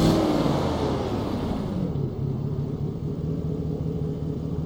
Index of /server/sound/vehicles/lwcars/chev_suburban
slowdown_highspeed.wav